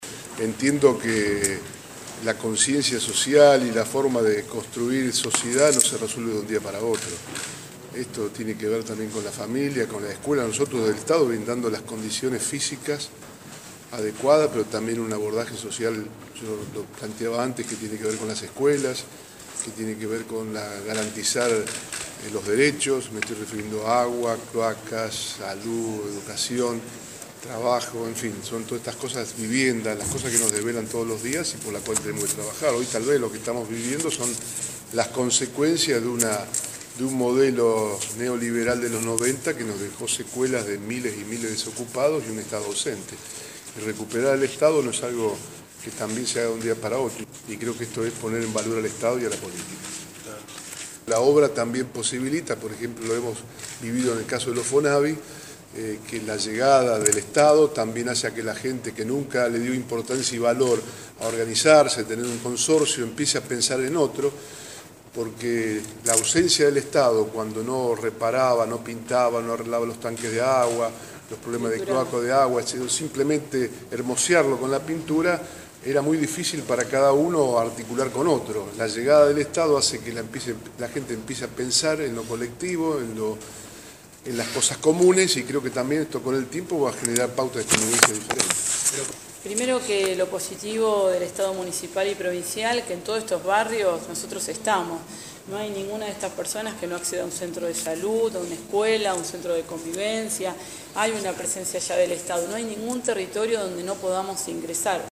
Declaraciones de Bonfatti y Fein.